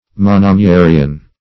Search Result for " monomyarian" : The Collaborative International Dictionary of English v.0.48: Monomyarian \Mon`o*my"a*ri*an\, Monomyary \Mon`o*my"a*ry\, a. (Zool.)